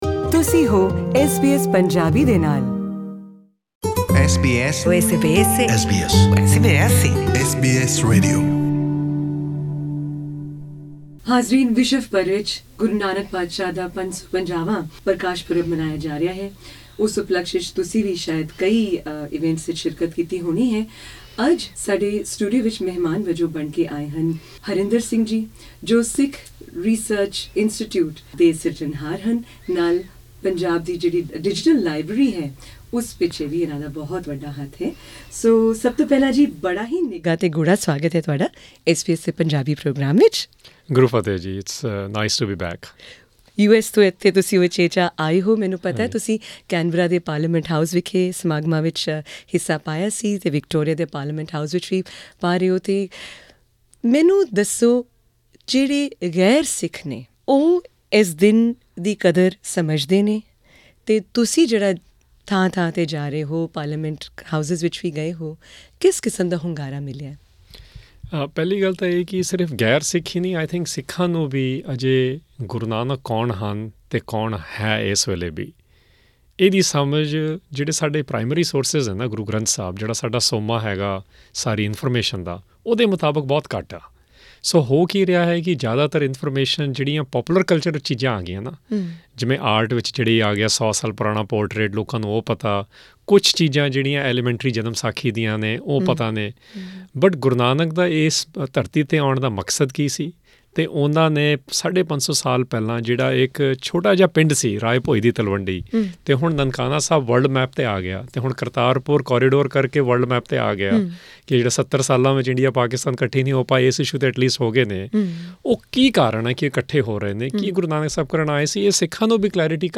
at SBS's Melbourne studios Source
interview